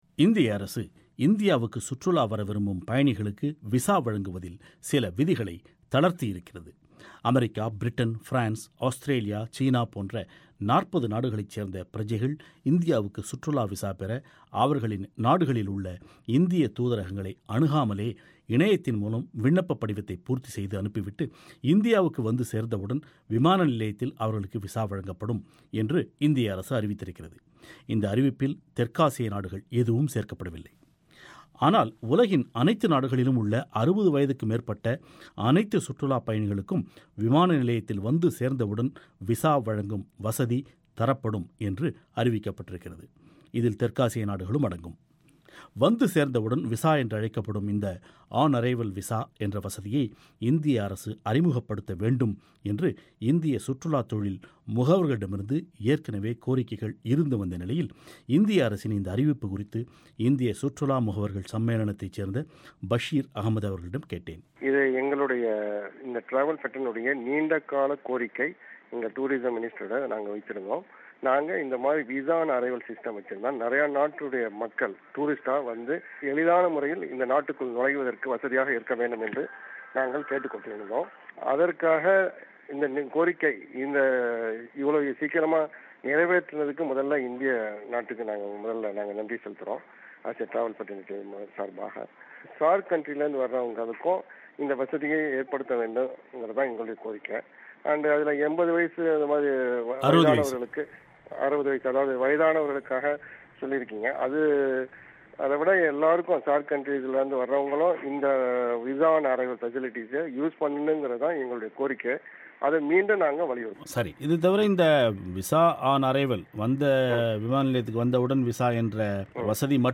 இது குறித்து இந்திய மற்றும் இலங்கை சுற்றுலா தொழில் தொடர்பானோரின் கருத்துக்களை மேலே உள்ள ஒலி இணைப்பில் கேட்கலாம்.